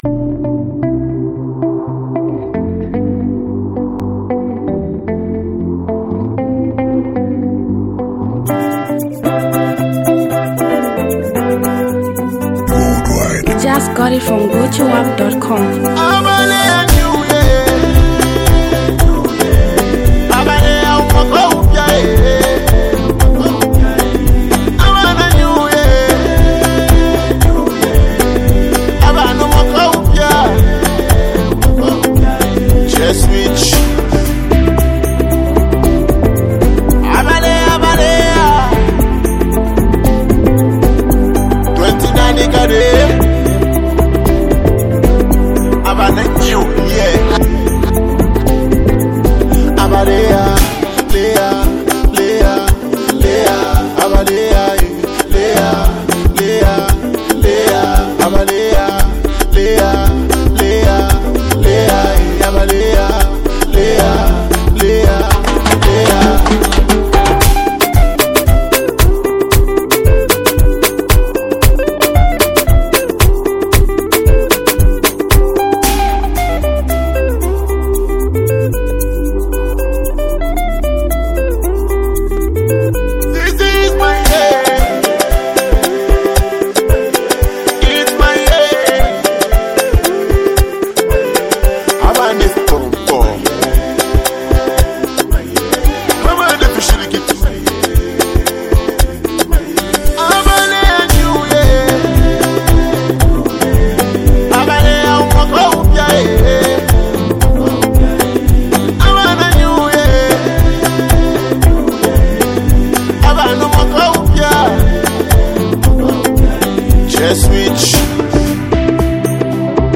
Zambian Mp3 Music
buzzing street record